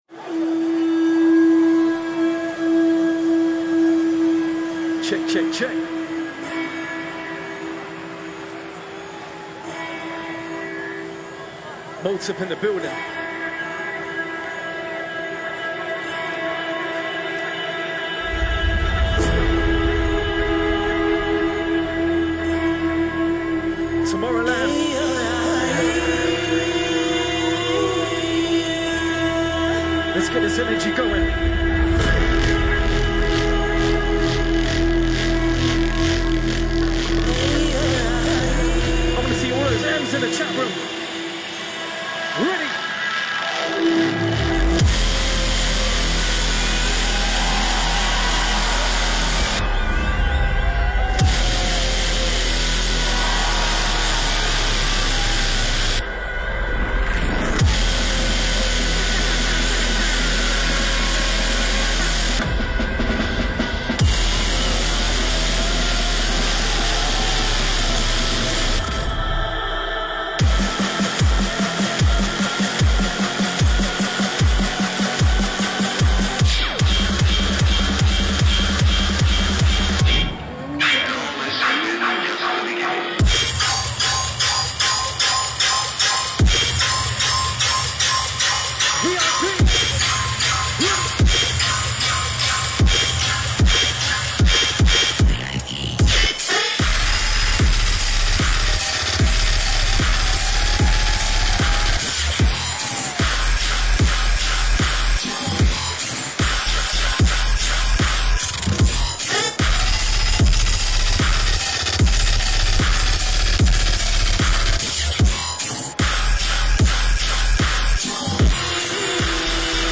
Genre: Dubstep